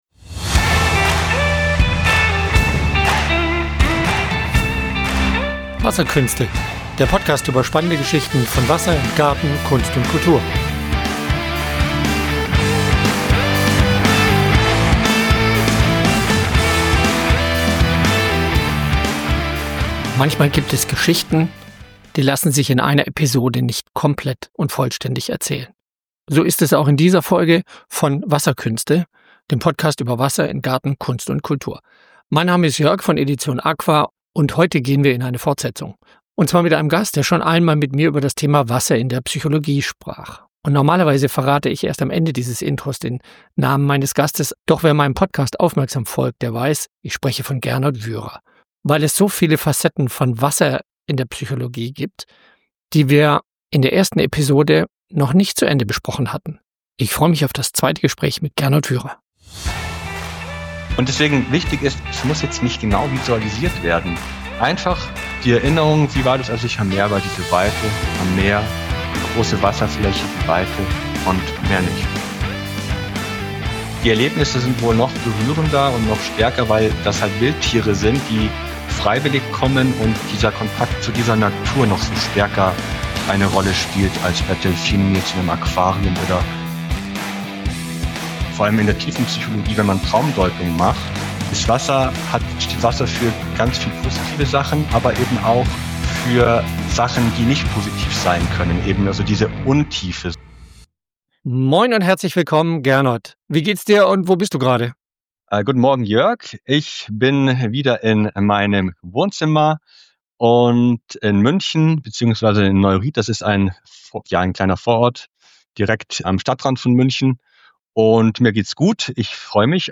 Fortsetzung des Interviews